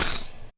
SHAKER I.wav